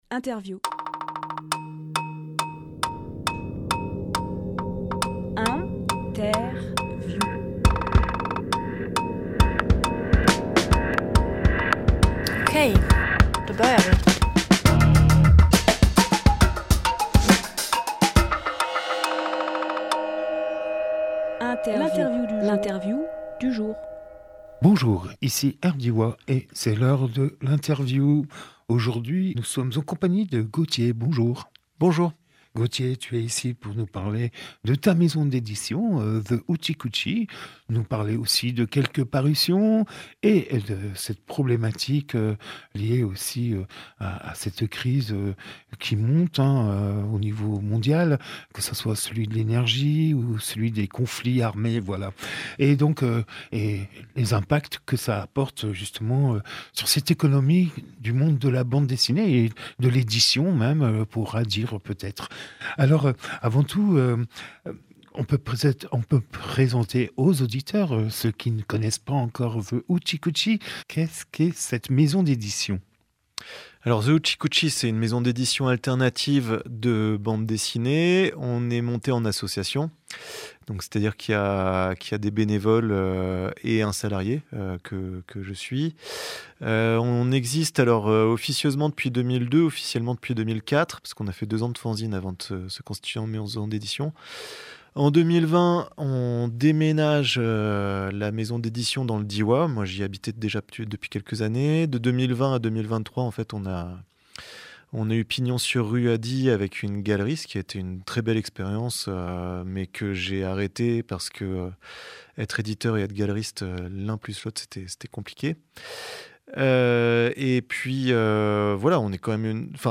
Emission - Interview Soutien à The Hoochie Coochie Publié le 24 mai 2024 Partager sur…
Lieu : Studio RDWA